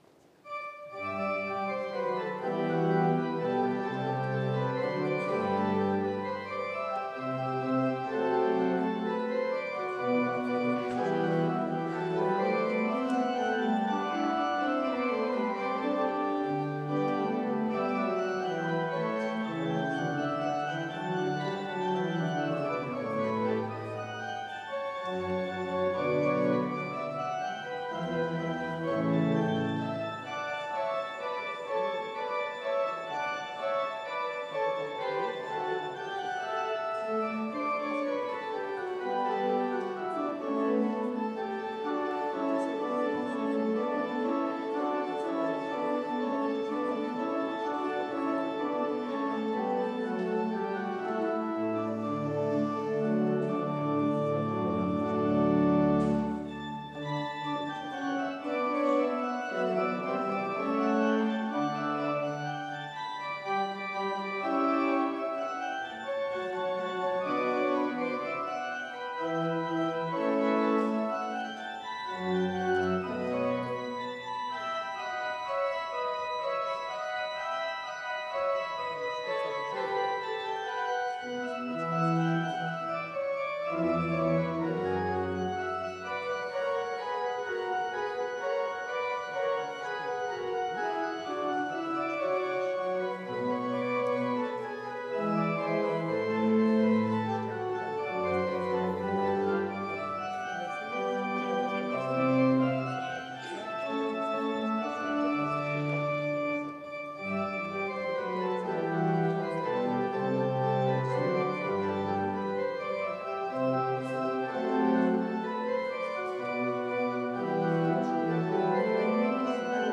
Orgelstück zum Ausgang
Audiomitschnitt unseres Gottesdienstes am Sonntag Miserikordias Domini (Hirtensonntag) 2023.